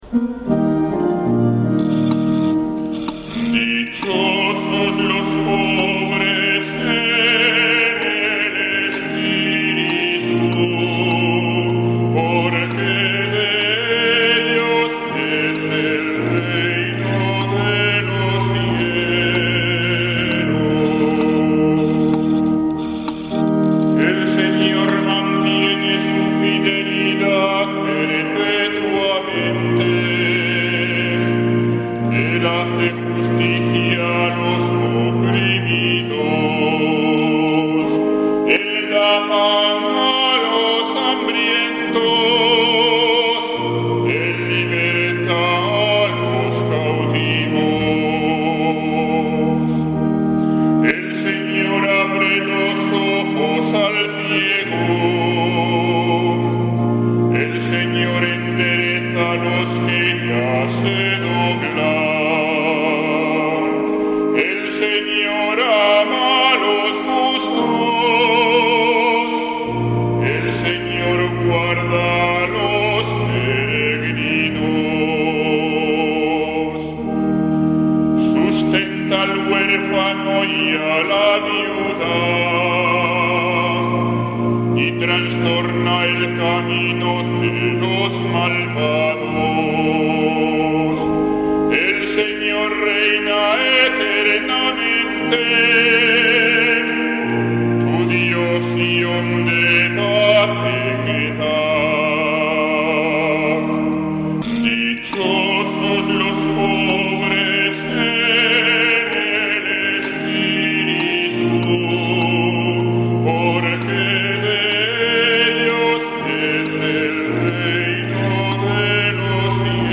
Salmo Responsorial 145/ 7-10